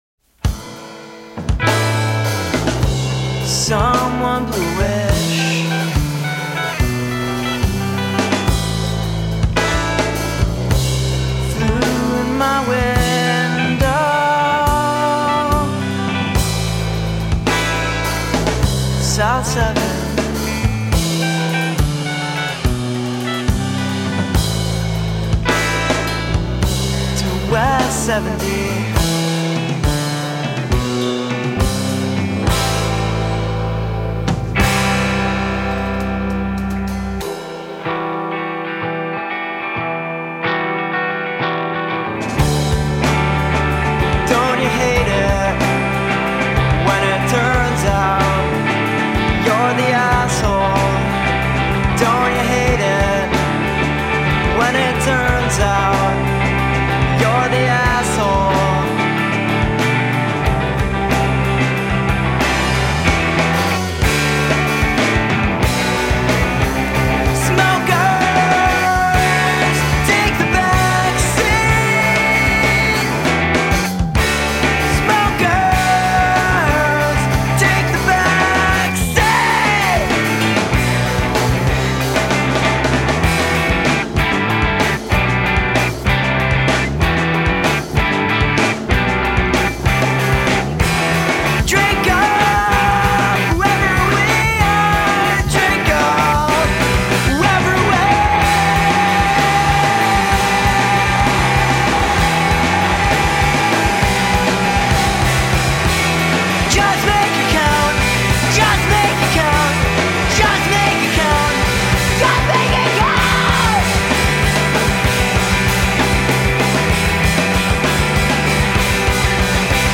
Energie+emotion a l'etat pur.